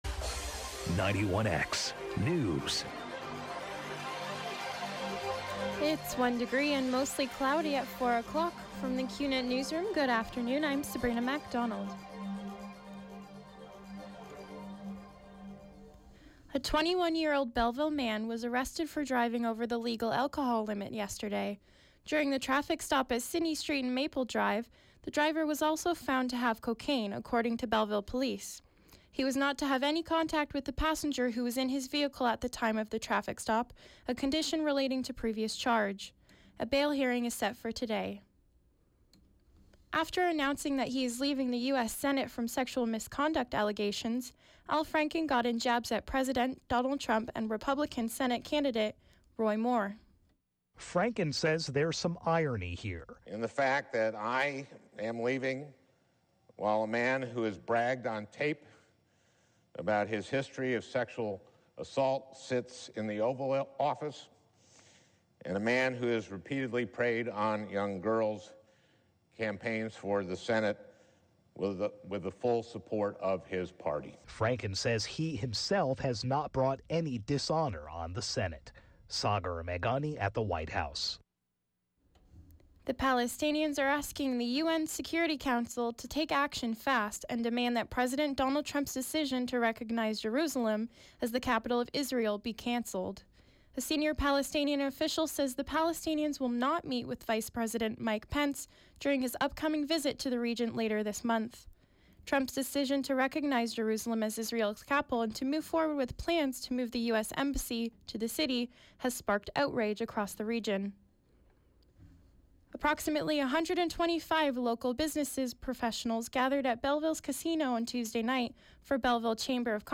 91X Newscast: Thursday, Dec. 7, 2017, 4 p.m.